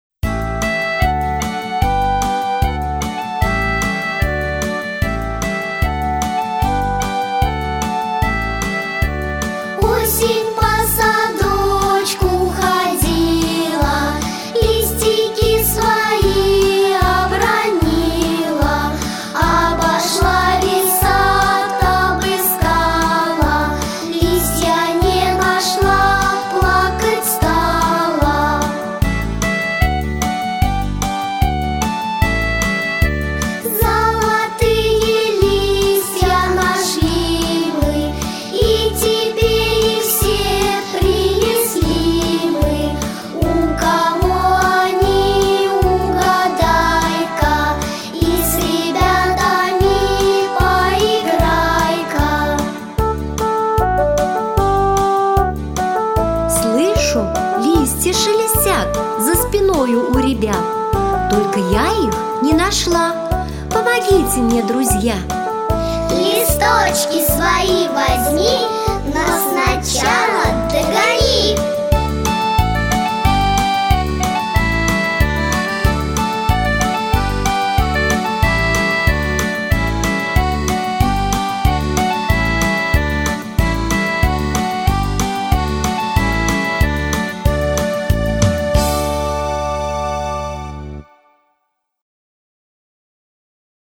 Украинская народная мелодия.